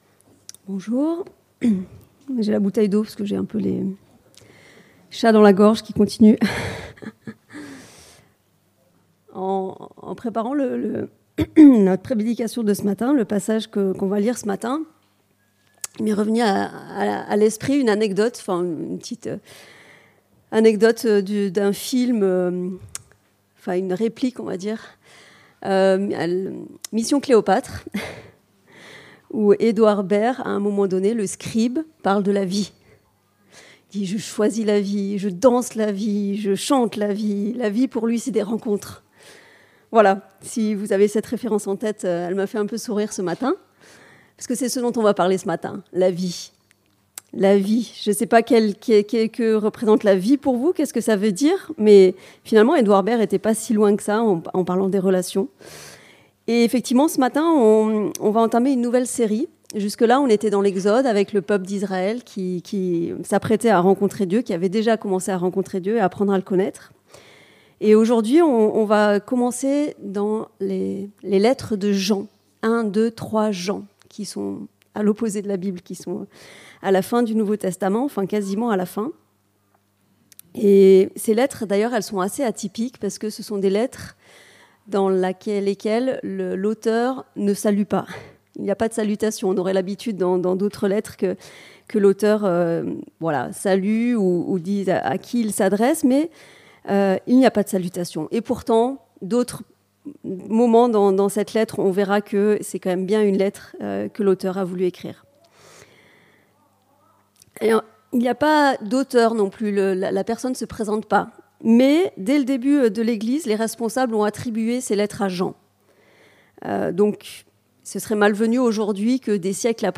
1 Jean Prédication textuelle